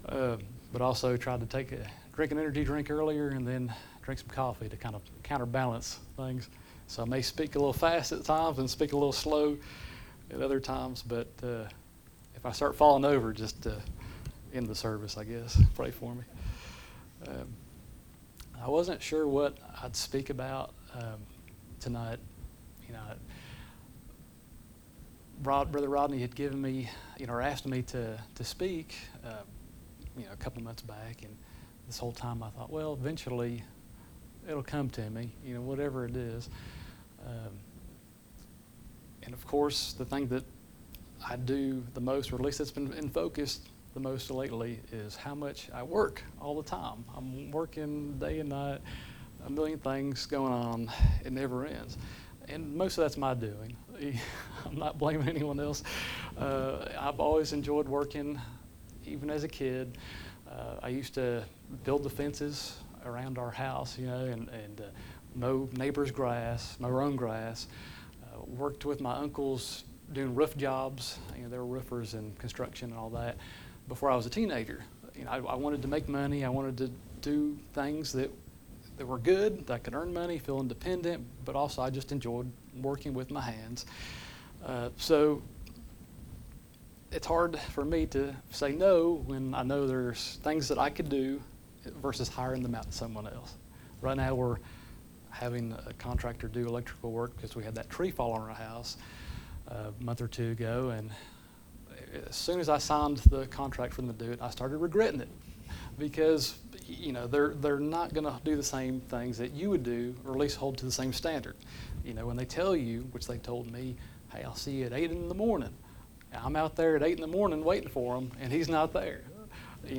Service Type: Special Services